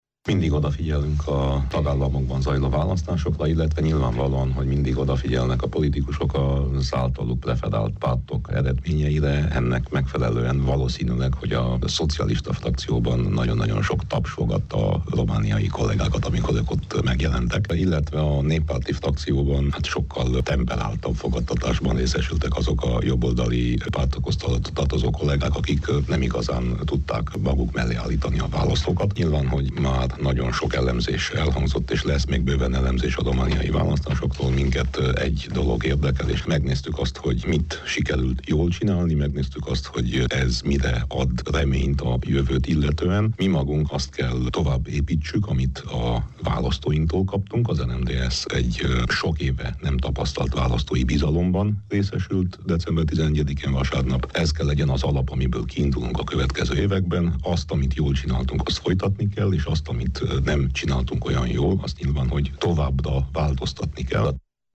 Winkler Gyula europarlamenti képviselő nyilatkozik arról, miként értékelte a testület a romániai parlamenti választások eredményét. Winkler Gyula a ma délelőtt Strasbourgból jelentkező Miben segíthetünk? című műsorunk meghívottja volt.